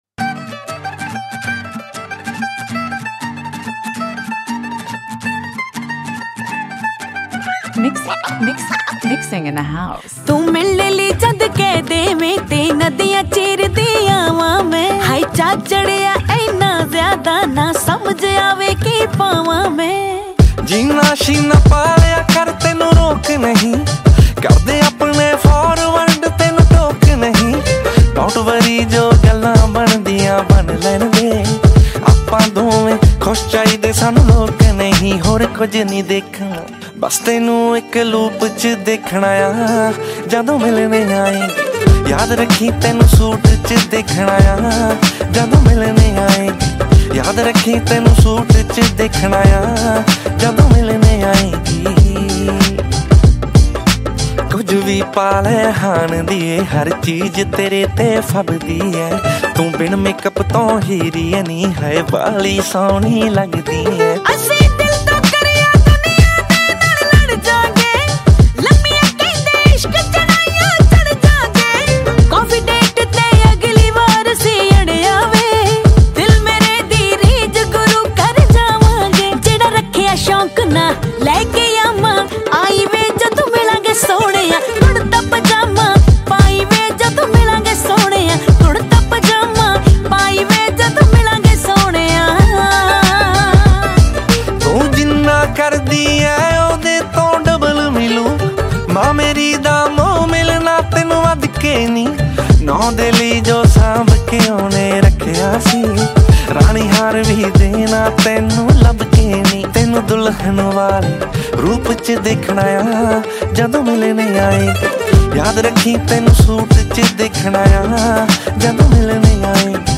Punjabi Single Track